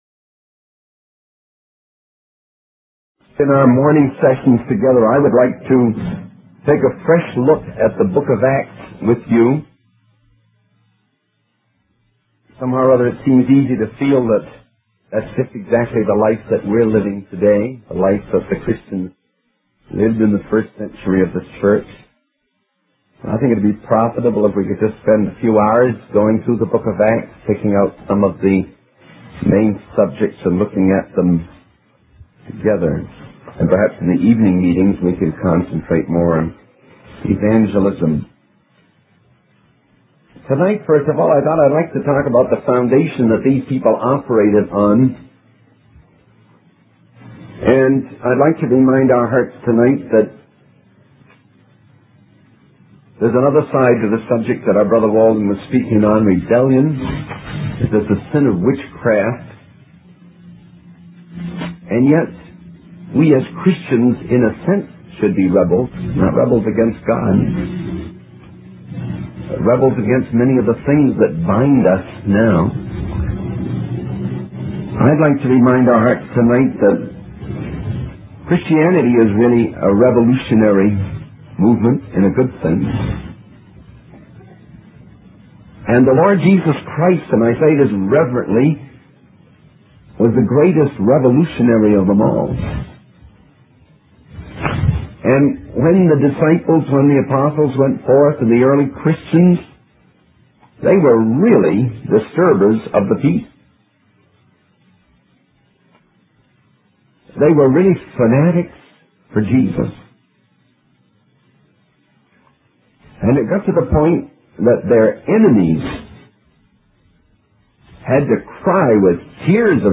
In this sermon, the speaker emphasizes the importance of living in a state of continuous revival and walking in the spirit. He believes that the principal work in service for Christ is done through prayer.